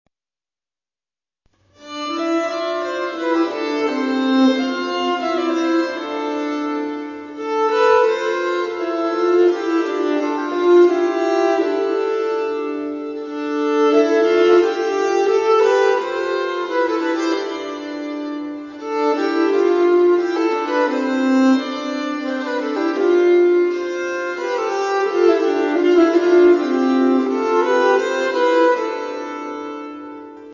Piece instrumentale
à deux vièles-XIII°s.
ALIA MUSICA